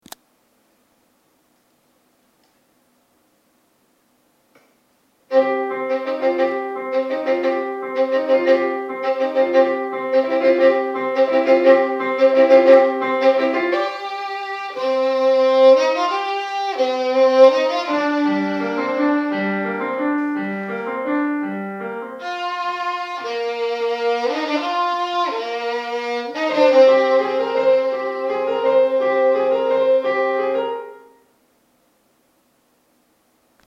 - Compás: 6/8.
- Tonalidad: Do menor
Violín